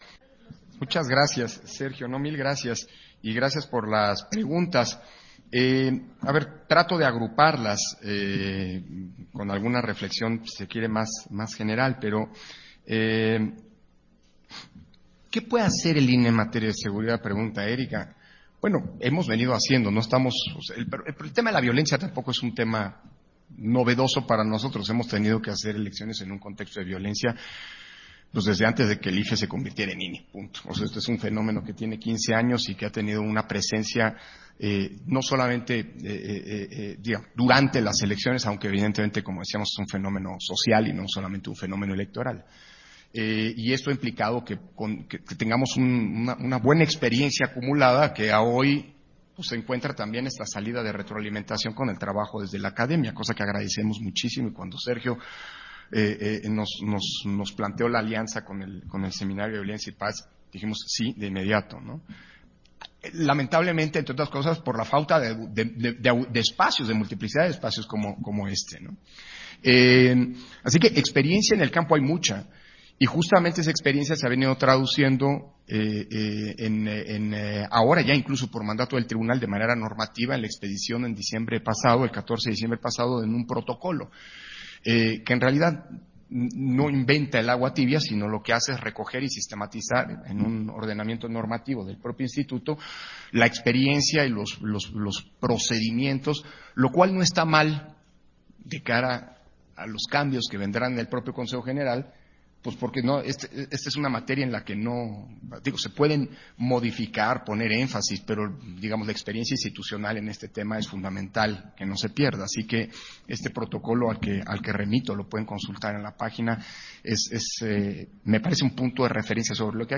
Intervenciones de Lorenzo Córdova, en la presentación de las recomendaciones para la seguridad de los candidatos y candidatas a los cargos de elección popular en las elecciones de Coahuila y Edomex 2023